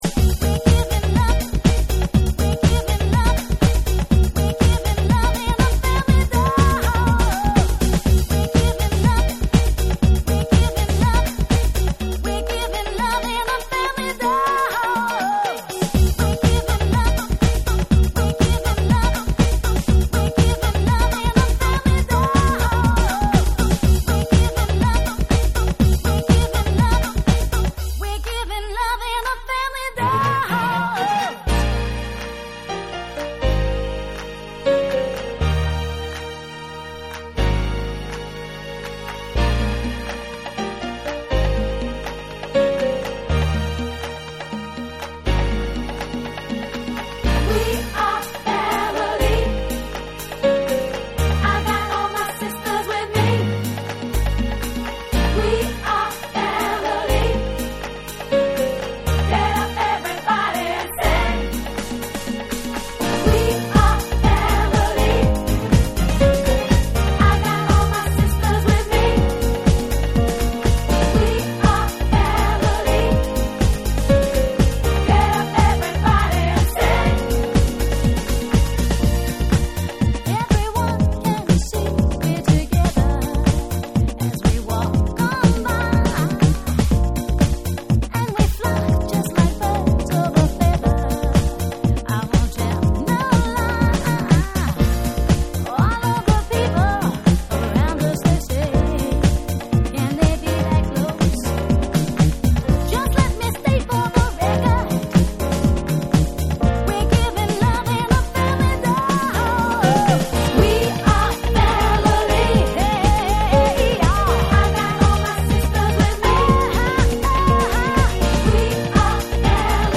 思わず口ずさんでしまうキャッチーなサビメロでお馴染みの多幸なディスコ・ナンバー
TECHNO & HOUSE / DANCE CLASSICS / DISCO